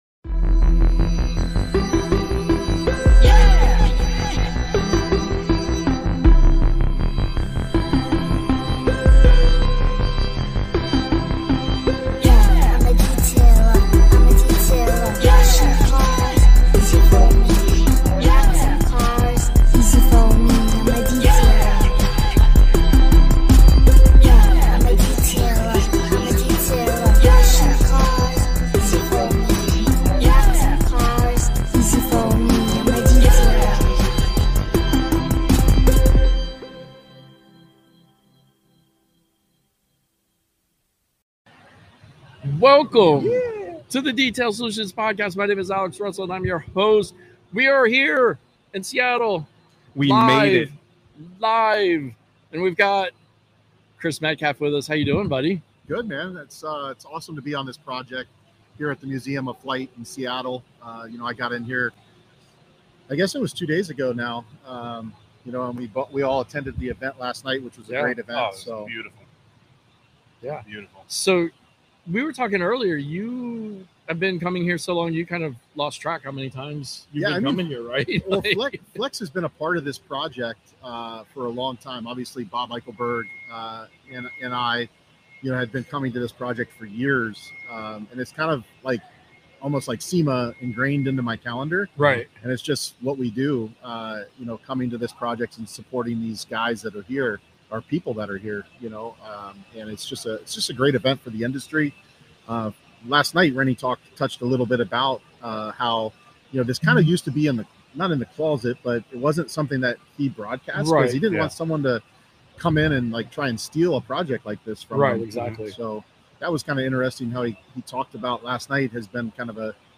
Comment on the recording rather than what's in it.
The museum is a cool place to be with so much history in aviation.